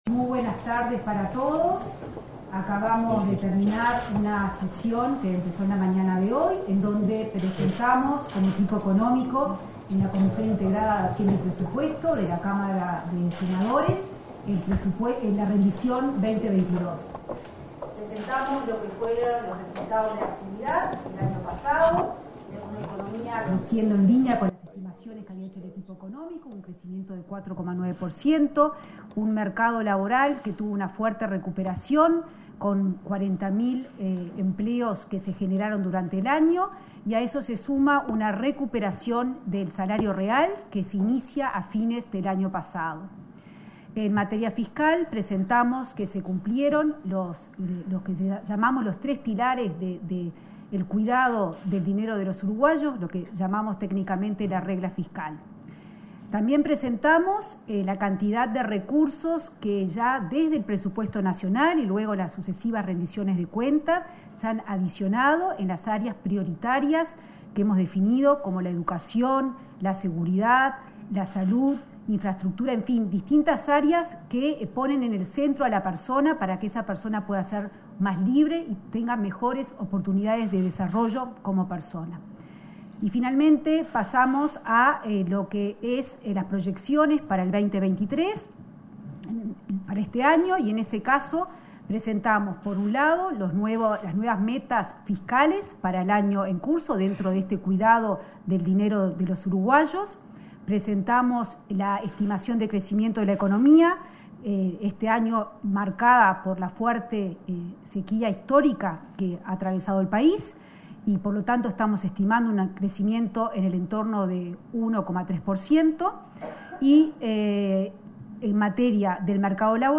Conferencia de prensa por la presentación de la Rendición de Cuentas en el Senado
El equipo económico, junto con autoridades de la Oficina de Planeamiento y Presupuesto y el Banco Central del Uruguay (BCU), asistió, este 29 de agosto, a la Comisión de Presupuesto Integrada con Hacienda del Senado, que discute la Rendición de Cuentas 2022 y las proyecciones para el resto del período. Luego, la ministra de Economía y Finanzas, Azucena Arbeleche, y el presidente del BCU, Diego Labat, informaron sobre el tema en conferencia de prensa.